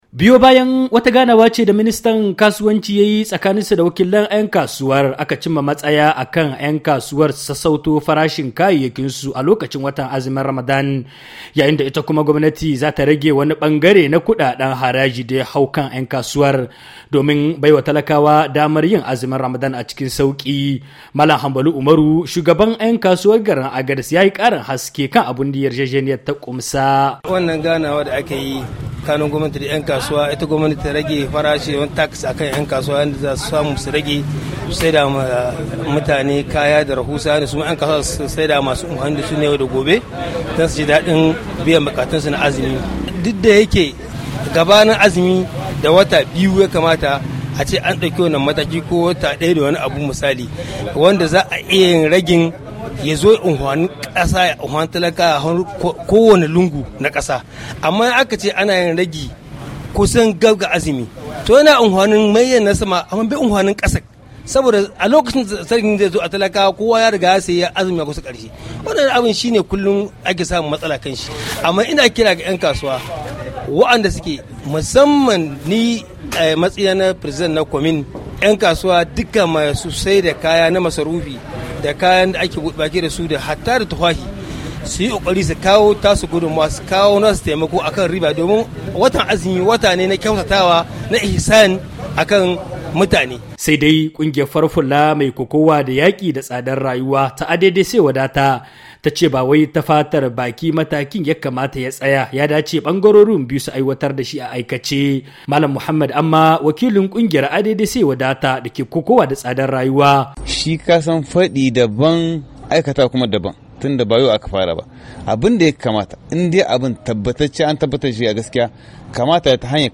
a cikin wannan rahoto